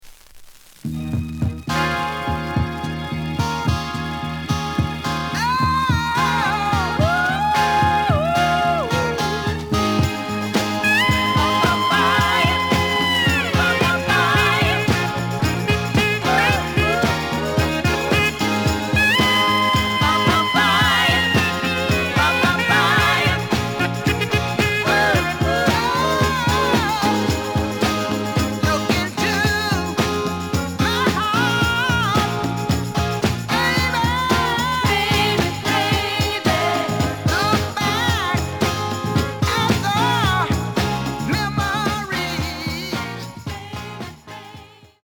The audio sample is recorded from the actual item.
Slight noise on beginning of A side.